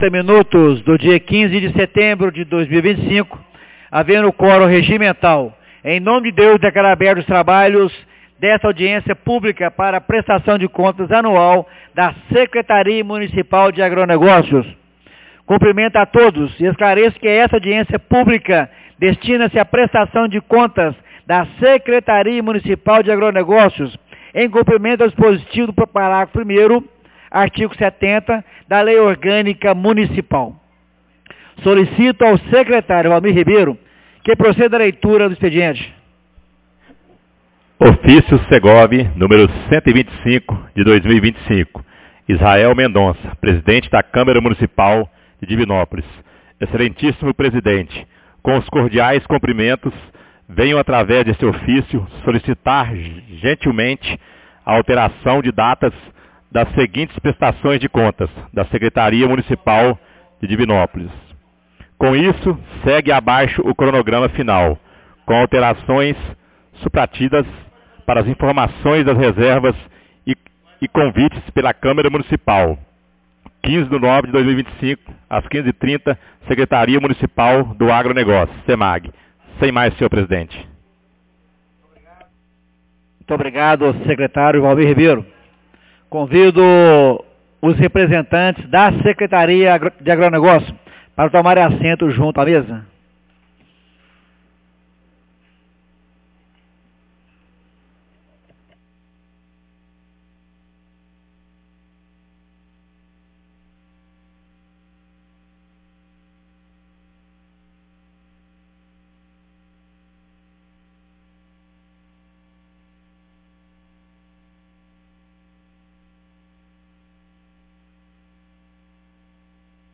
Audiencia publica Prestação de Contas da Sec Mun de Agronegocios 15 de setembro de 2025